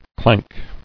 [clank]